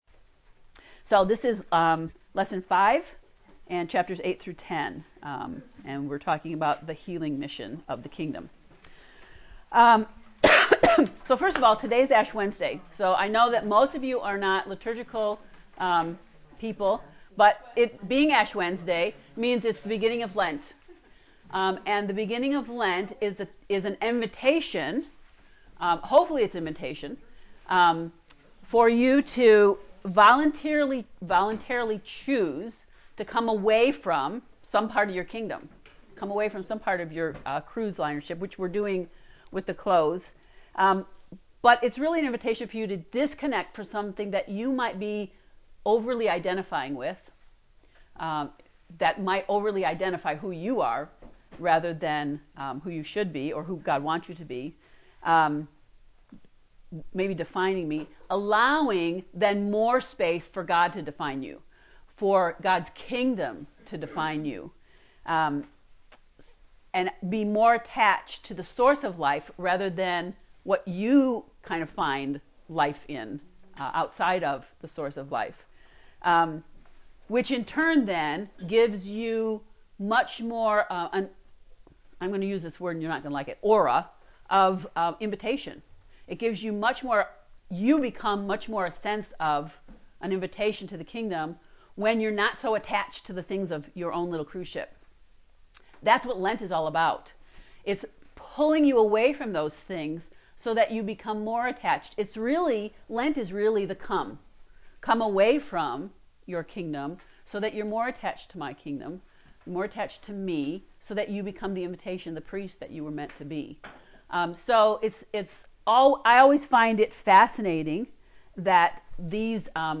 To listen to Lesson 5 lecture “The Healing Mission” click here: